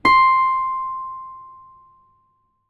ZITHER C 4.wav